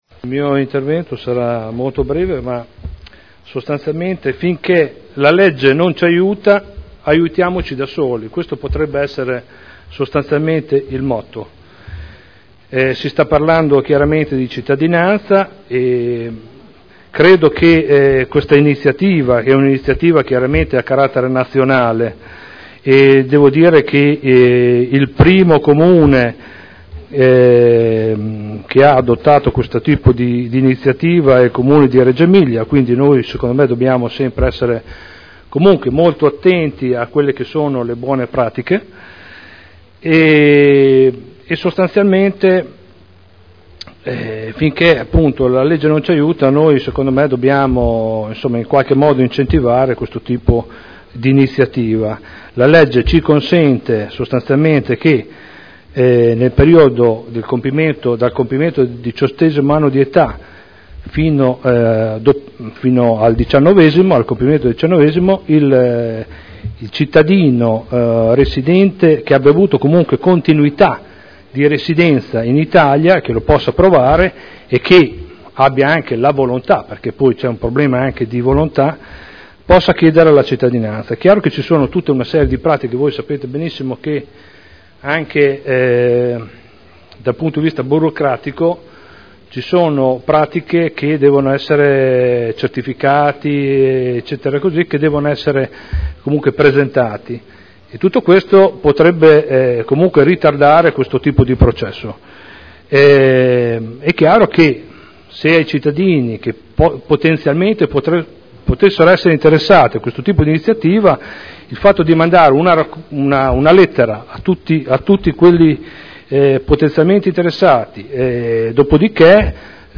Seduta del 16 aprile. Mozione presentata dai consiglieri Prampolini, Artioli, Trande, Garagnani, Goldoni, Pini, Glorioso, Gorrieri, Sala, Guerzoni, Rocco, Codeluppi, Cotrino, Campioli, Cornia, Morini, Rimini, Rossi F., Dori (P.D.) avente per oggetto: “Fratelli in Italia” Dichiarazioni di voto